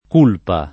Culpa [ k 2 lpa ]